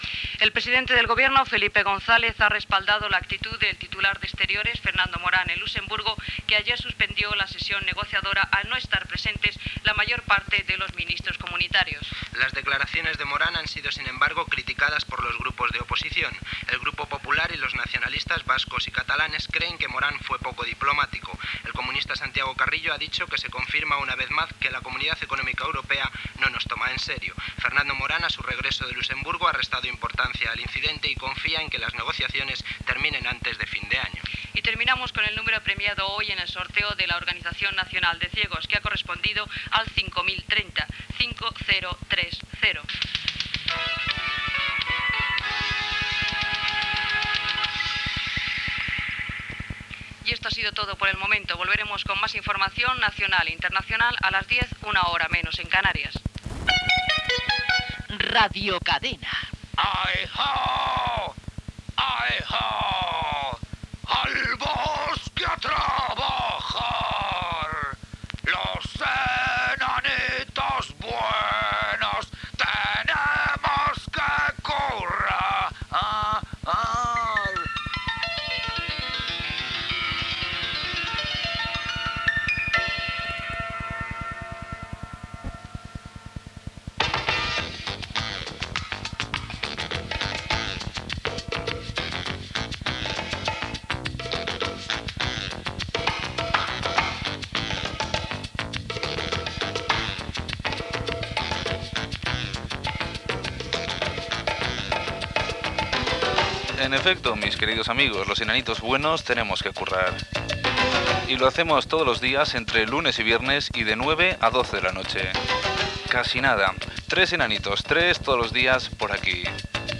Final de les notícies (Fernando Morán, número de l'ONCE), indicatiu de l'emissora i inici del programa amb els continguts previstos.
Entreteniment
FM